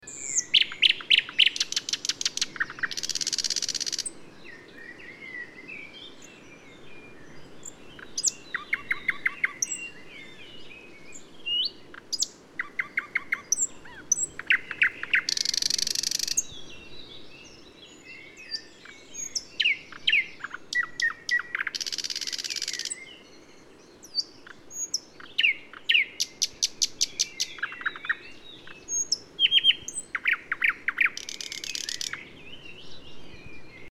PFR02116, 090512, Thrush Nightingale Luscinia luscinia, song – singing in a former testing ground for V2 rockets